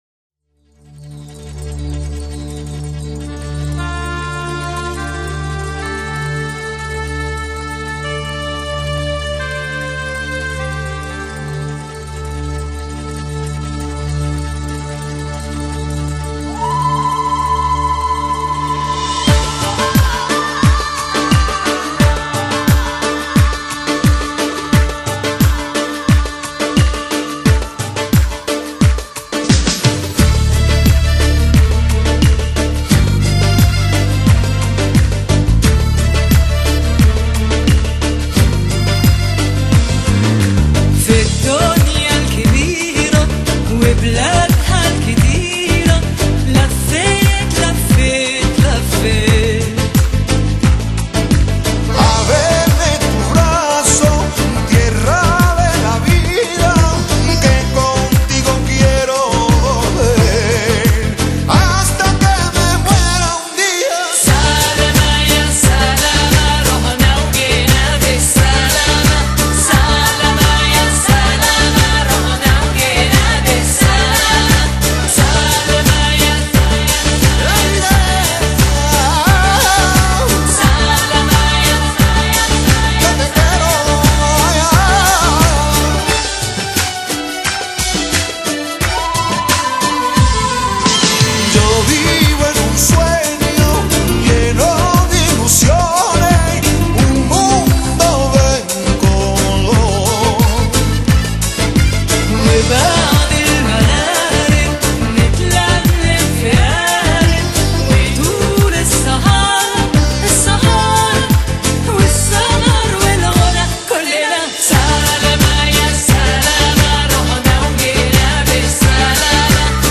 Género: Pop, Remix